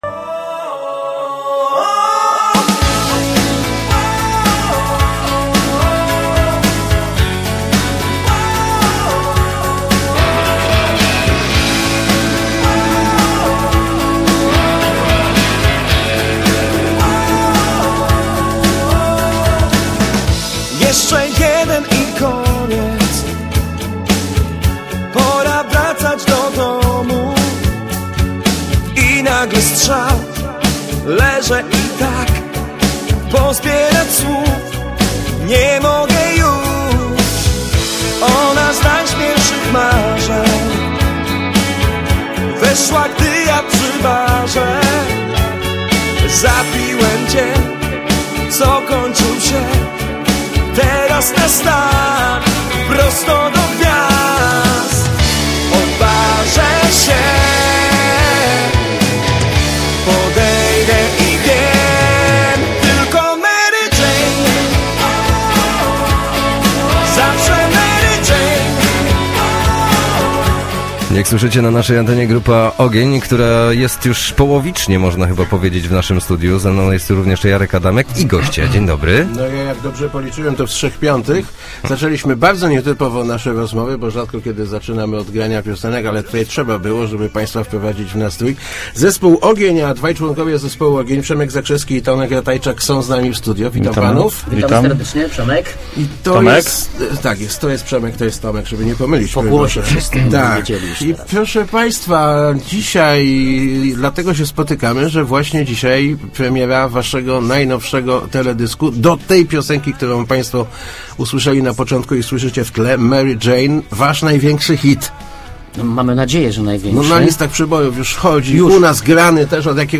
Start arrow Rozmowy Elki arrow Rock z Kościana